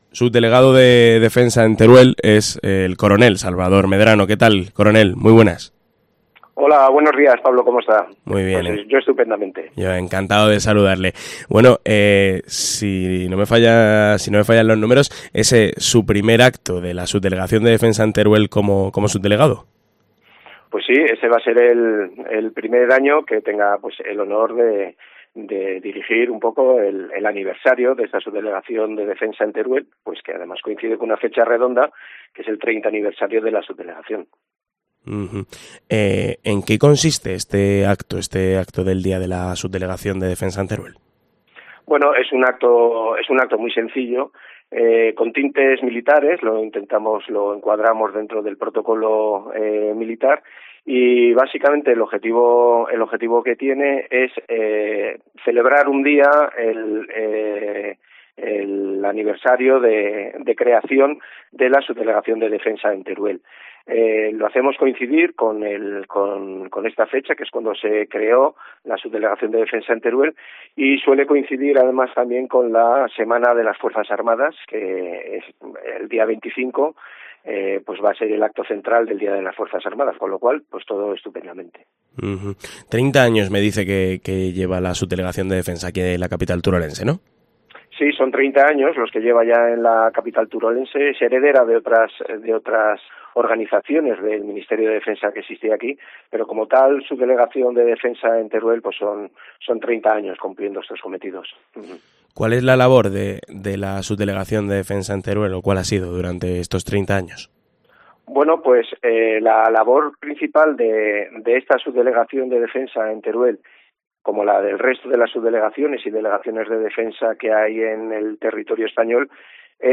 Entrevista al coronel Salvador Medrano, subdelegado de Defensa en Teruel